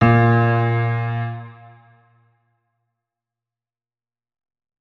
piano
notes-22.ogg